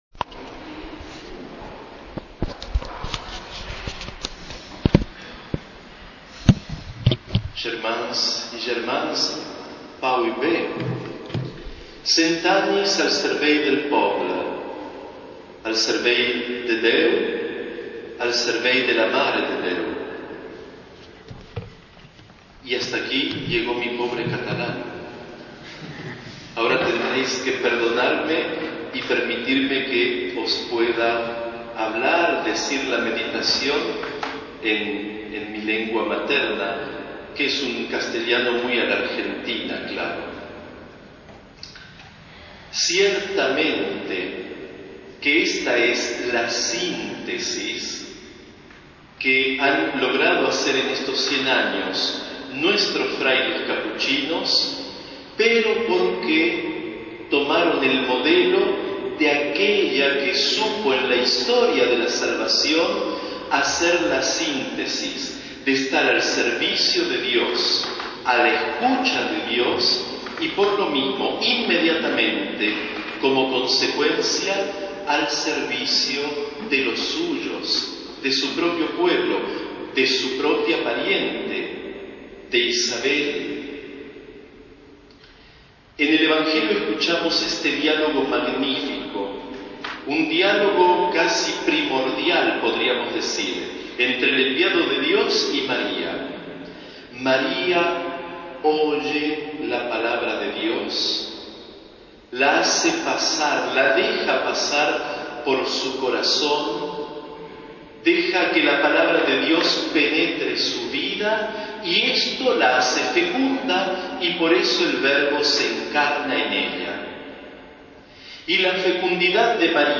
Homilia.mp3